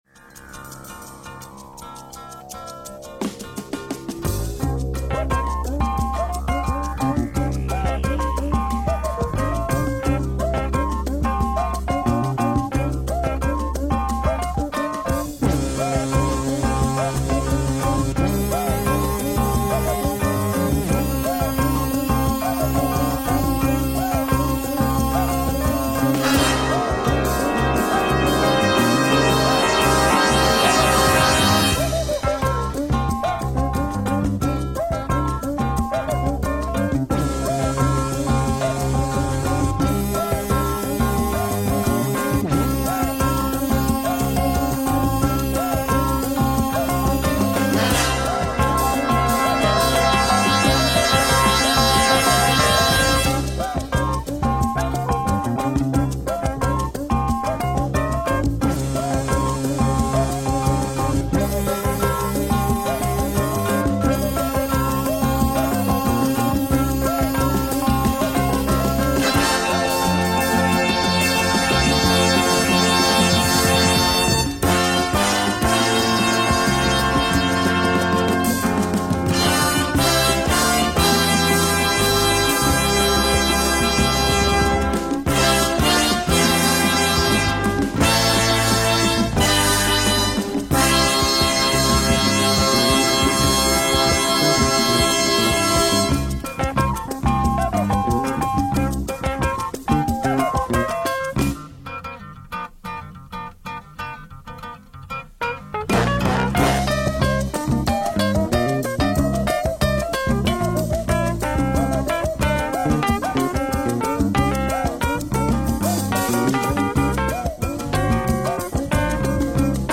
Brazilian moog funk, latin jazz or groovy bossa nova !